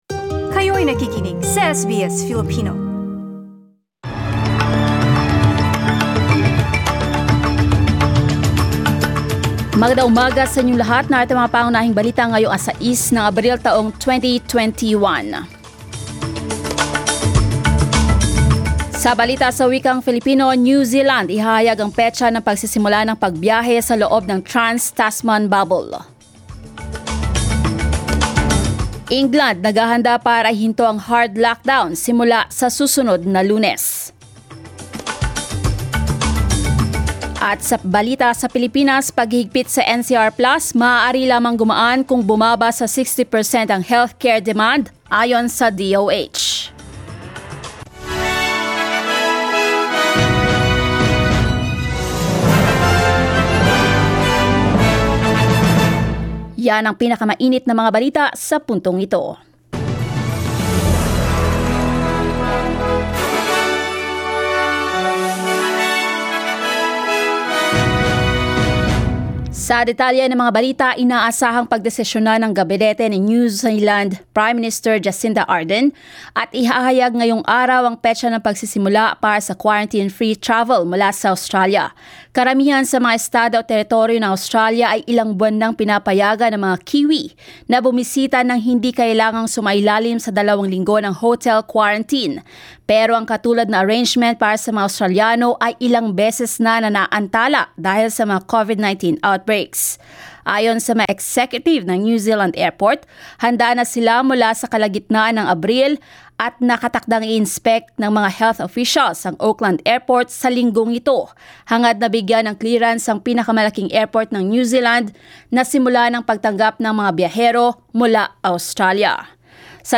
SBS News in Filipino, Tuesday 6 April